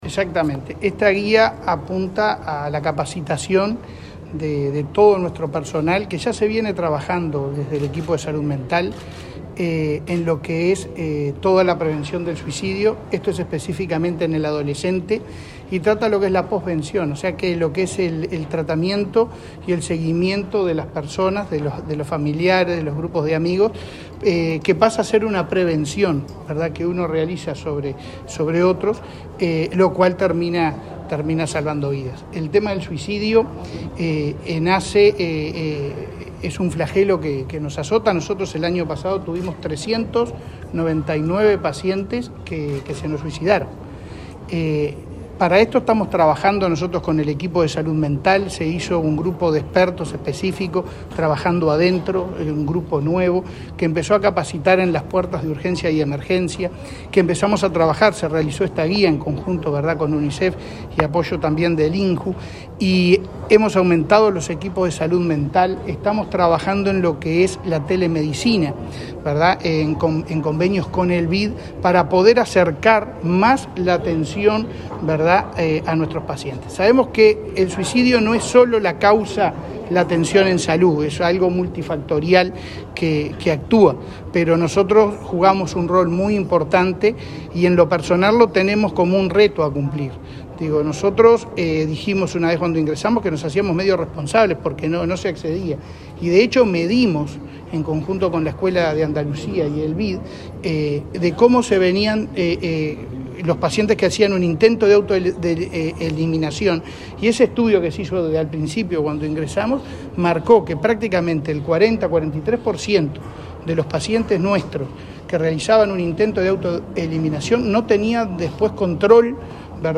Declaraciones del presidente de ASSE a la prensa
Luego, dialogó con la prensa.